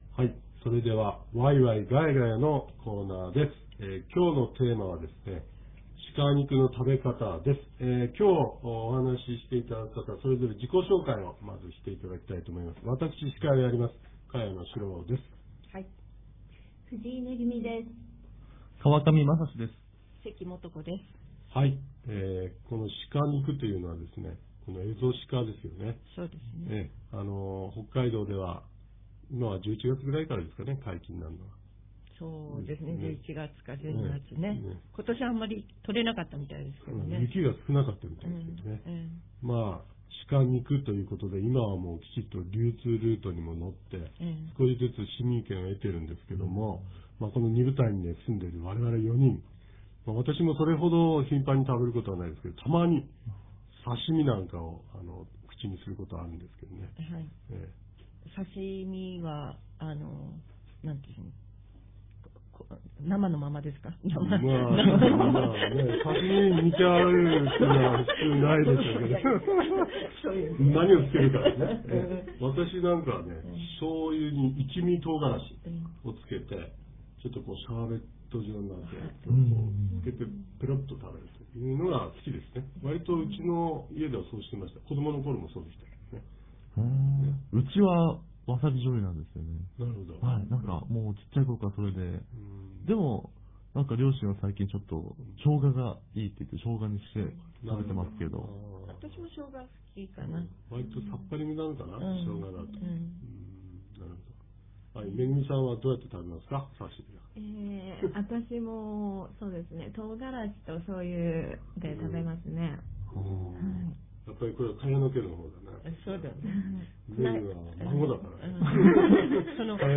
■アイヌ語ワンポイントレッスン（３５）